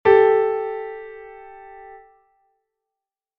Disonancia 2ª M (Sol - La)